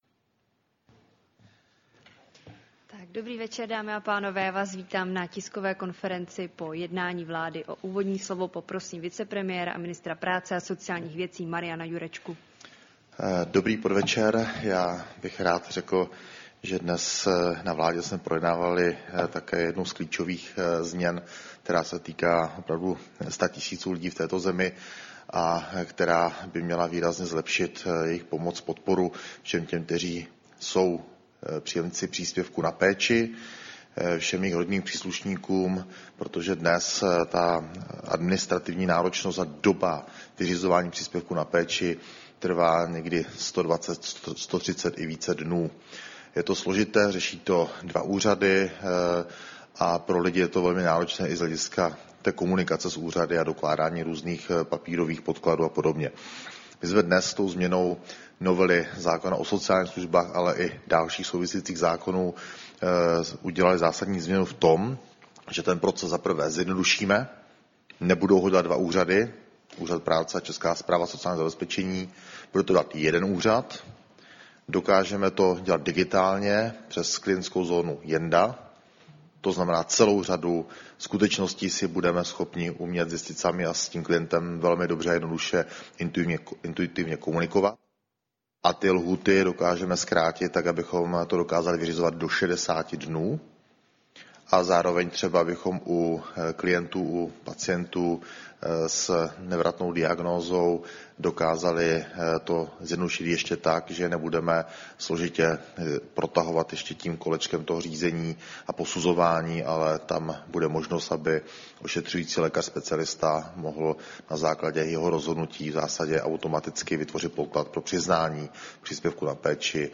Tisková konference po jednání vlády, 11. září 2024